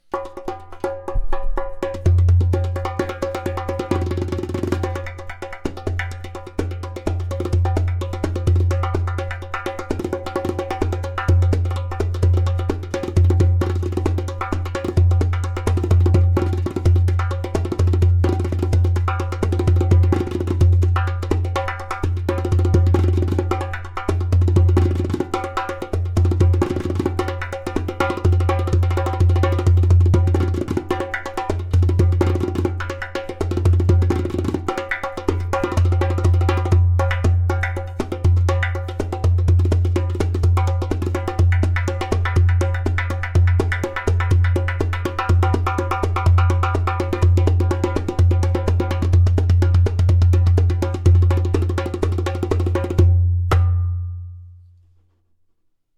P r e m i u m Line Darbuka
In this exclusive line, materials such as clay, glaze, and natural goat skin come together in a magical harmony, giving life to a balanced, resonant sound.
• High sound clear “taks”.
• Deep bass
• Very strong clay “kik”/click sound